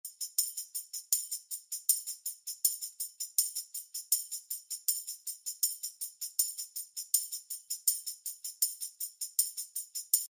Звуки тамбурина
Звук С с 16 джинглами